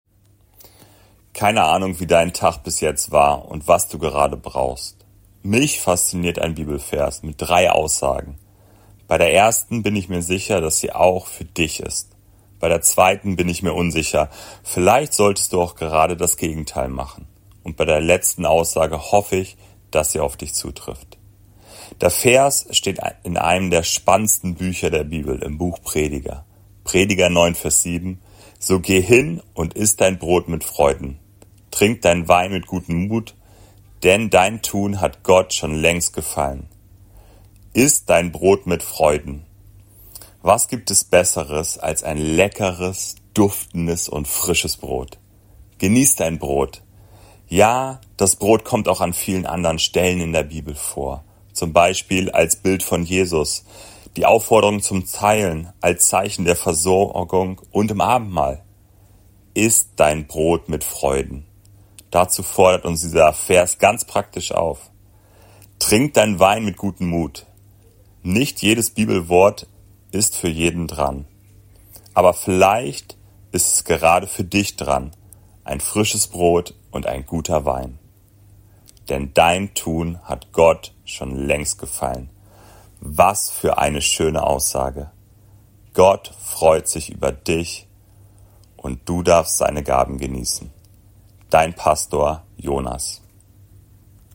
Andacht